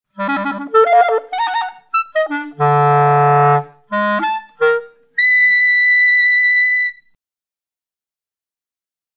Alban Berg FOUR PIECES FOR CLARINET AND PIANO, Op. 5
•  intensification